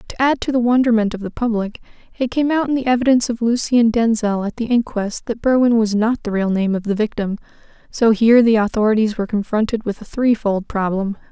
female_high_voice.wav